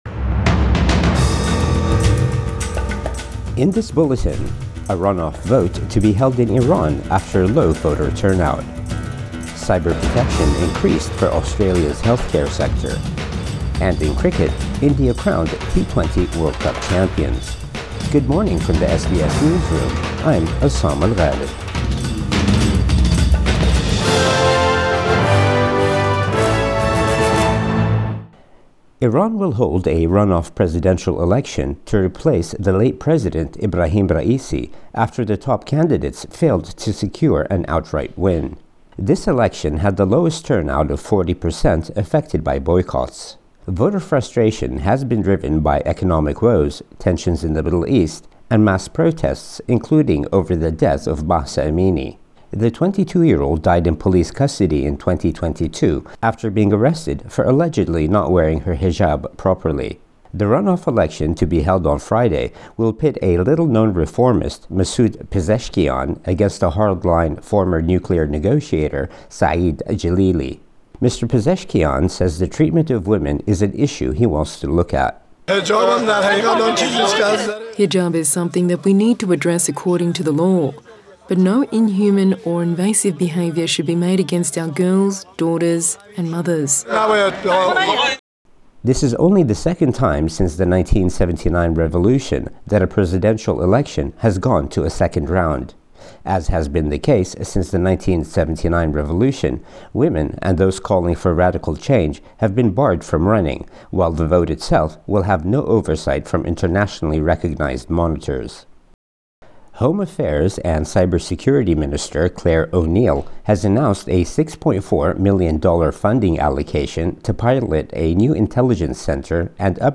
Morning News Bulletin 30 June 2024